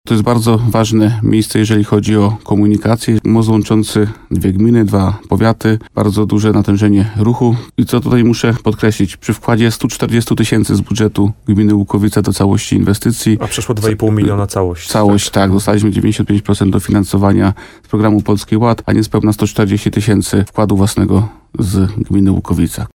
– Budowa mostu była kluczową inwestycją dla naszej gminy – mówił w programie Słowo za słowo na antenie RDN Nowy Sącz wójt Łukowicy Bogdan Łuczkowski.